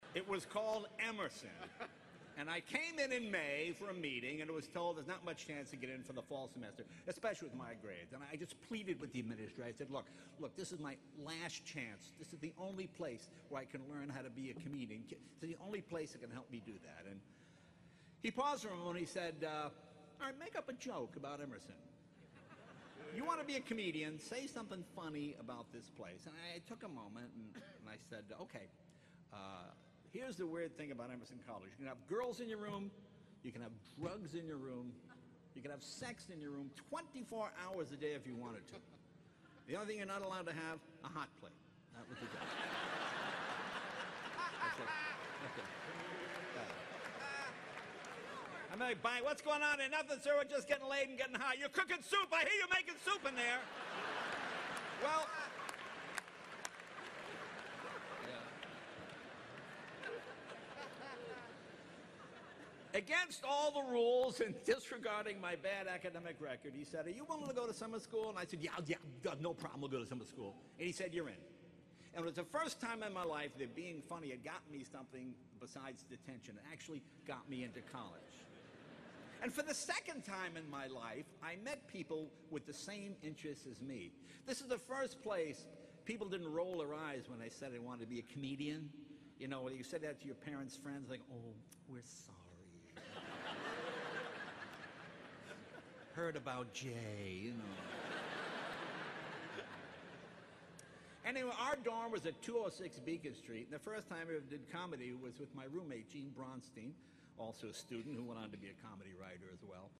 公众人物毕业演讲 第205期:杰雷诺2014爱默生学院(3) 听力文件下载—在线英语听力室